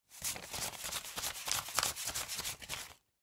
Звуки долларов